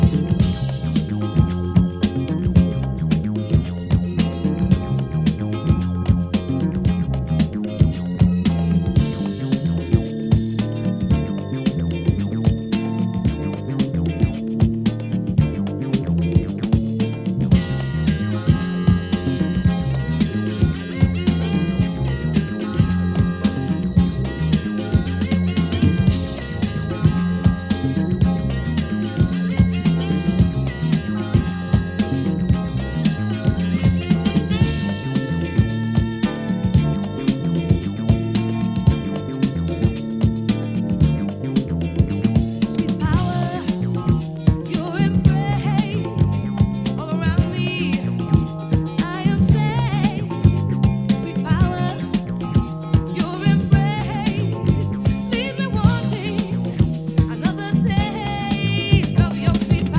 ジャンル(スタイル) RARE GROOVE / JAZZ FUNK / FUSION